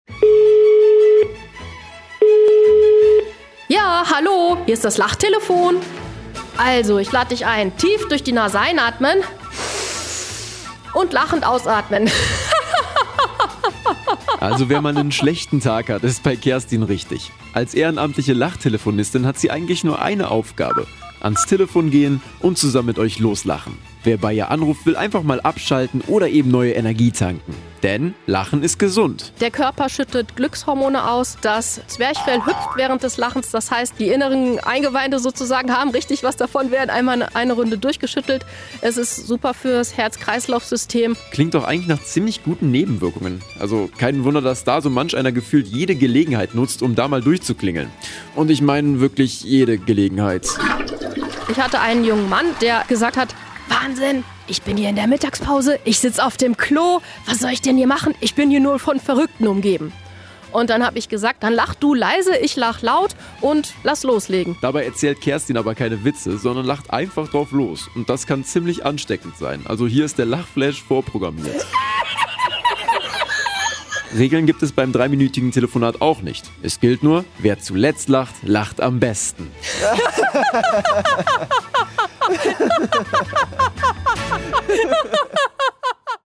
Nach einer kurzen Begrüßung wird dann auch schon völlig grundlos und wild zusammen losgegackert.